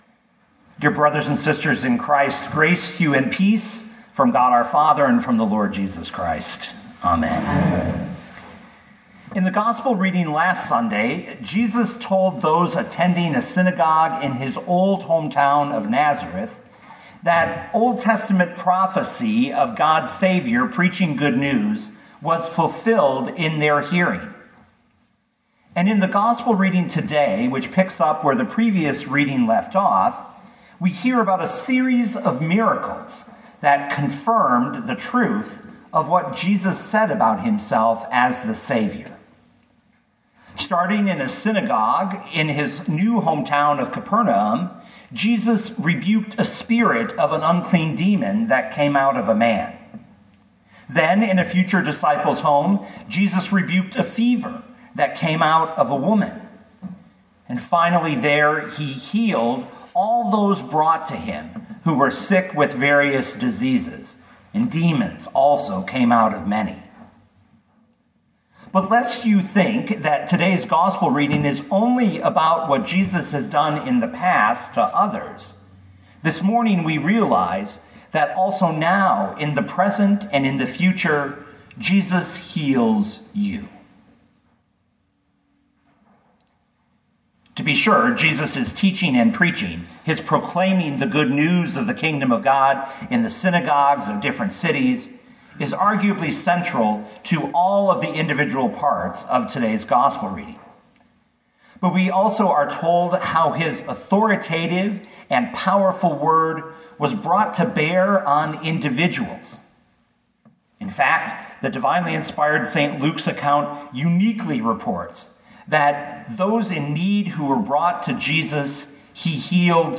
2022 Luke 4:31-44 Listen to the sermon with the player below, or, download the audio.